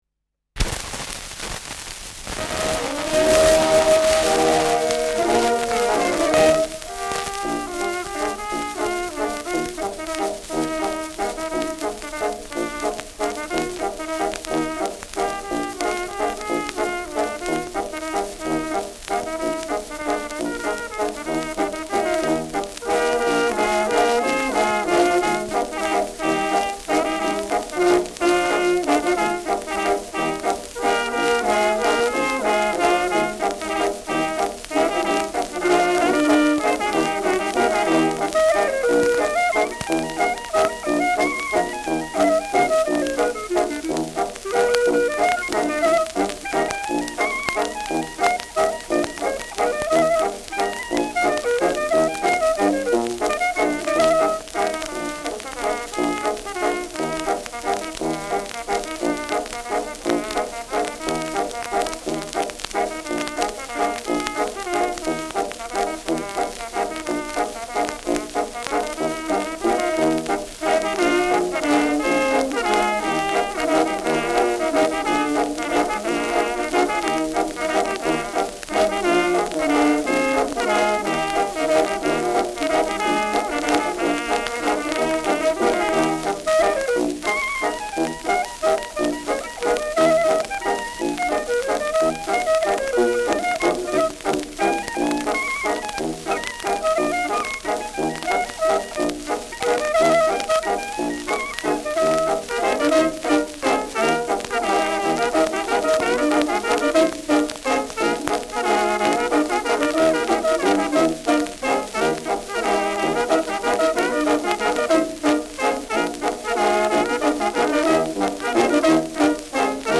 Schellackplatte
präsentes Rauschen : präsentes Knistern : abgespielt : gelegentliches Knacken : „Schnarren“ : leichtes Leiern
Dachauer Bauernkapelle (Interpretation)
[München] (Aufnahmeort)